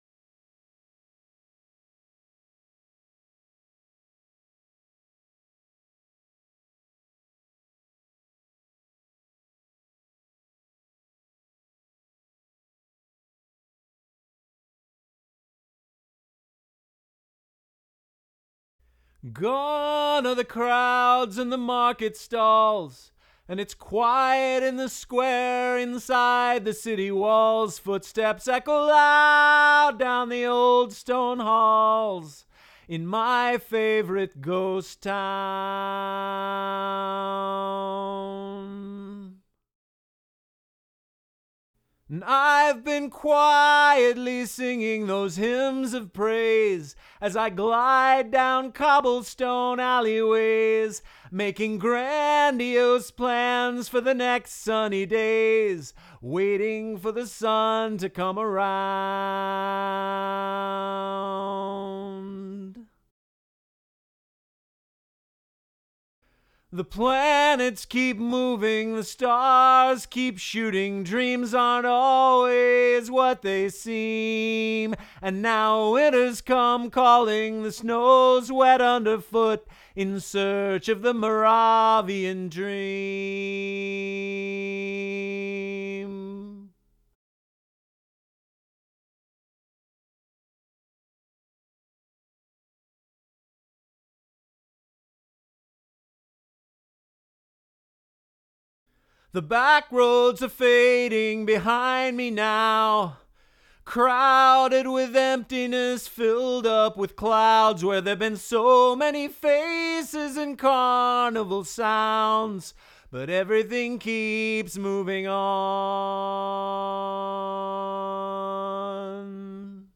leadvocal.wav